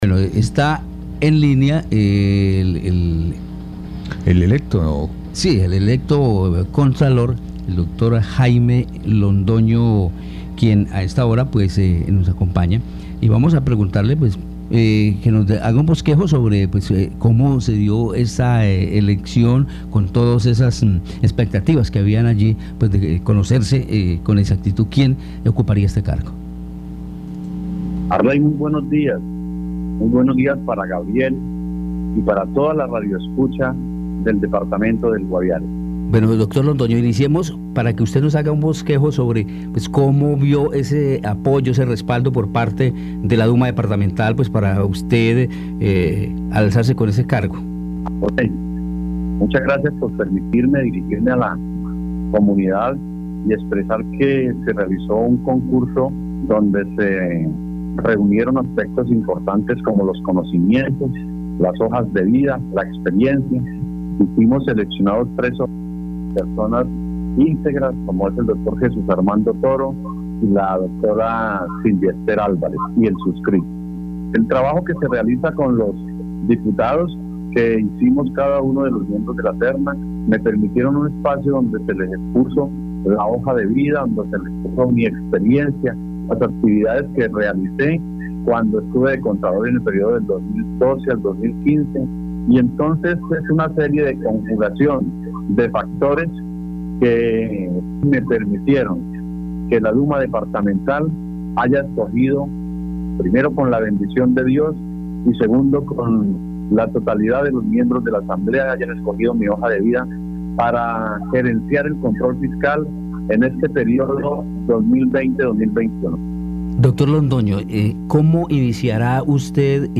Escuche a Jaime Londoño, nuevo contralor Departamental del Guaviare.